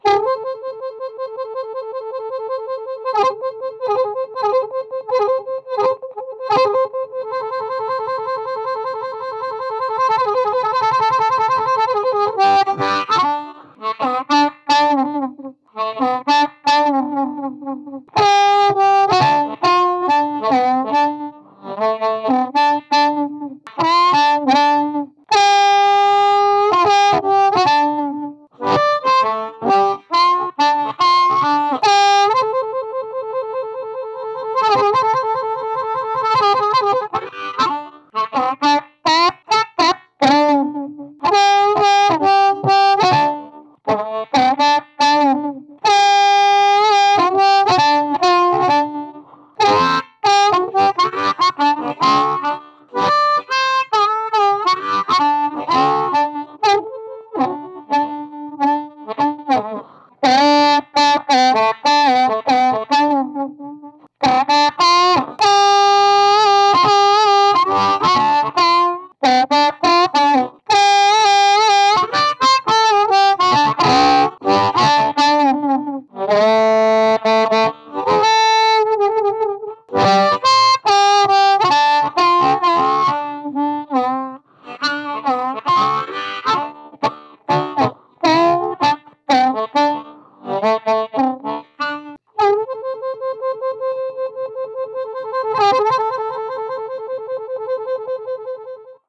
It goes from sparkling clean to brown and creamy distortion.
Back track harp only - G Marine Band